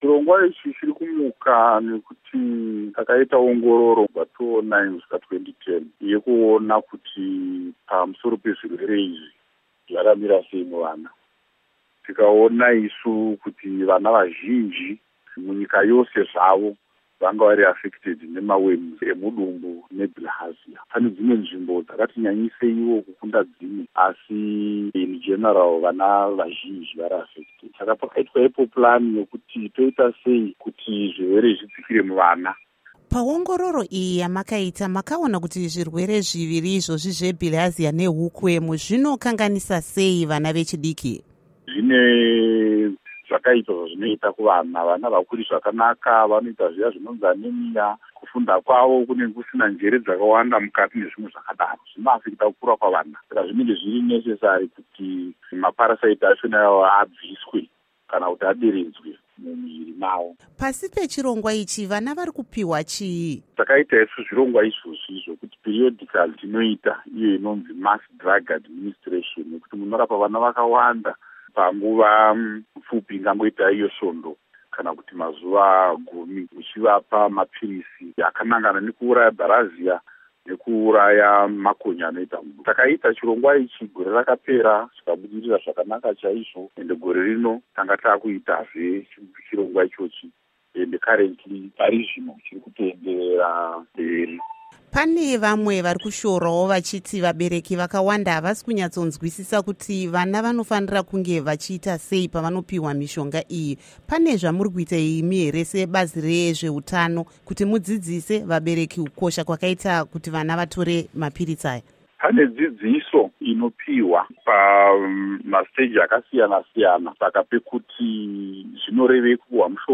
Hurukuro NaDr Gerald Gwinji